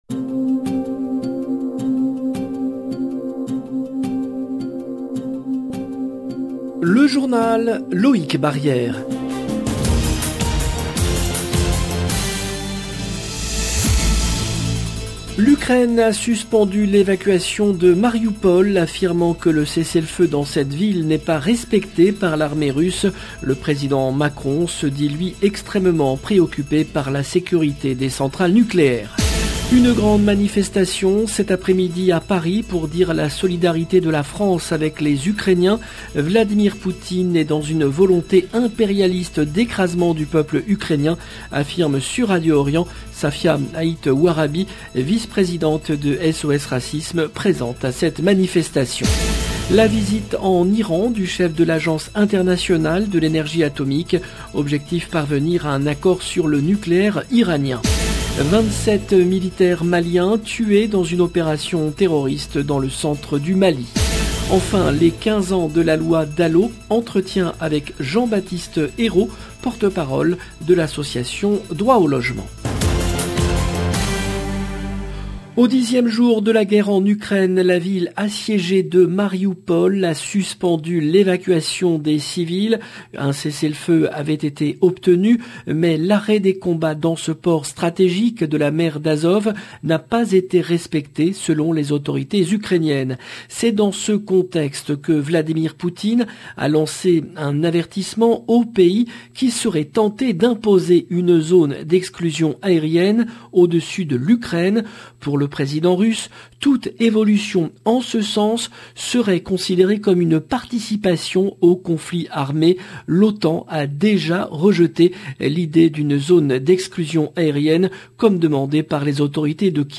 LE JOURNAL DU SOIR EN LANGUE FRANCAISE DU 5/05/22